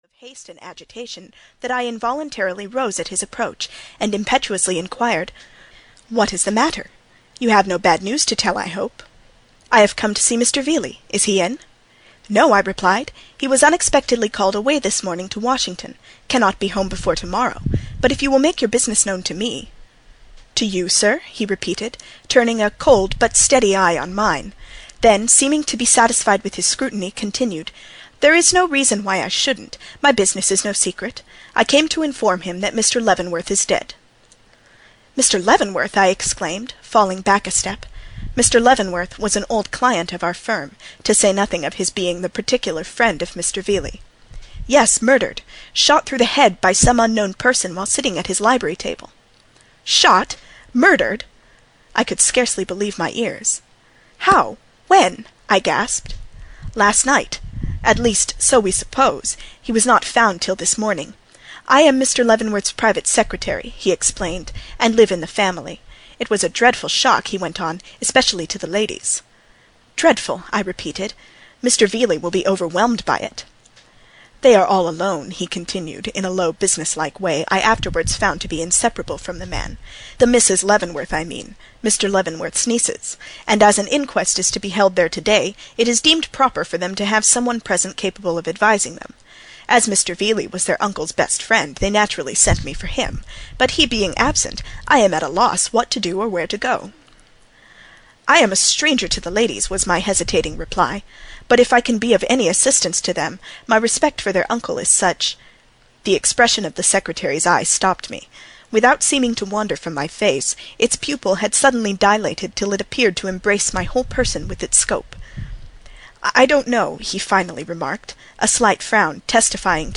The Leavenworth case (EN) audiokniha
Ukázka z knihy